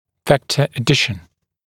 [‘vektə ə’dɪʃn][‘вэктэ э’дишн]сложение векторов